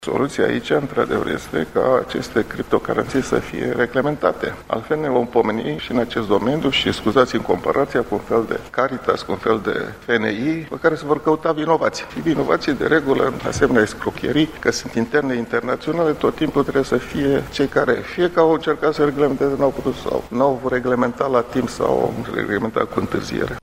Cu ocazia lansării volumului, Mugur Isărescu a vorbit şi despre necesitatea reglementării criptomonedelor: